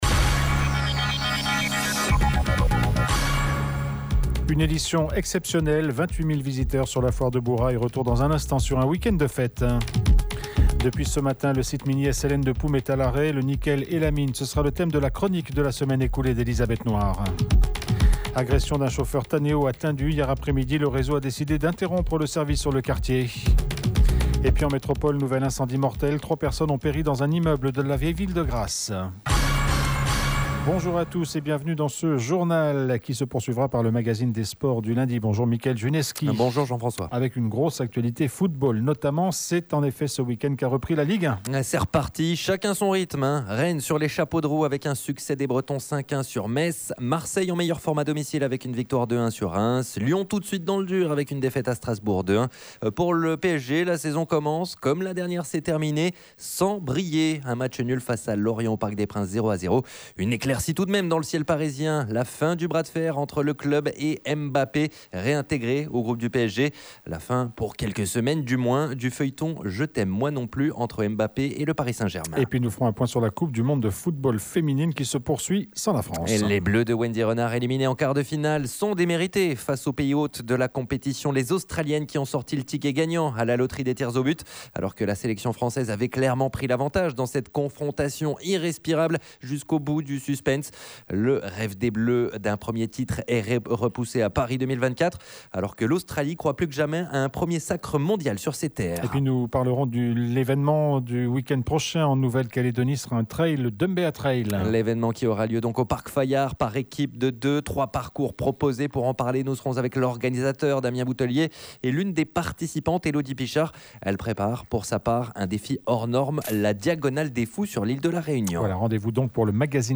JOURNAL : INFO MAG LUNDI